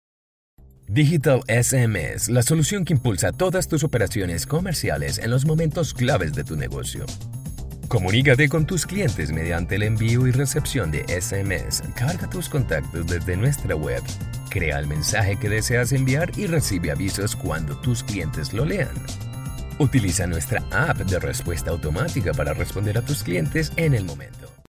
I'll gladly take care of your voice-over requirements (Neutral Latin American Spanish/Neutral American English), be it for your personal or commercial use.
Sprechprobe: Industrie (Muttersprache):